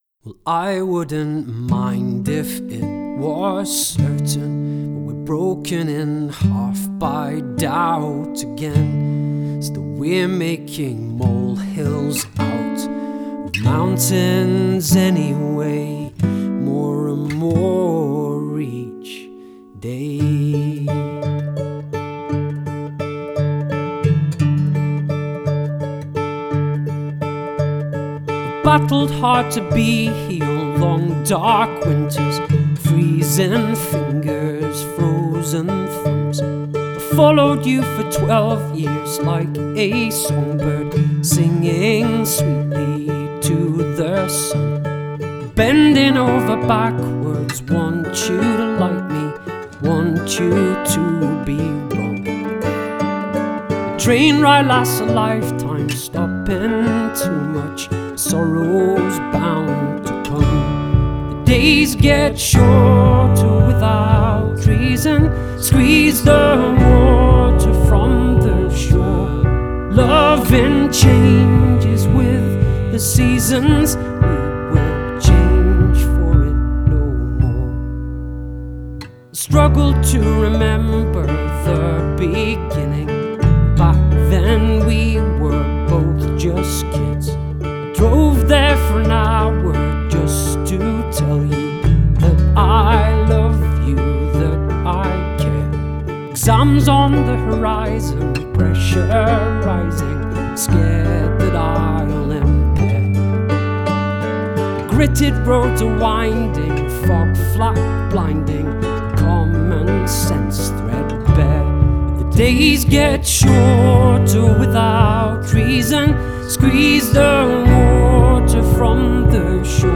Genre: Indie Rock/ Indie Folk